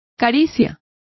Complete with pronunciation of the translation of caress.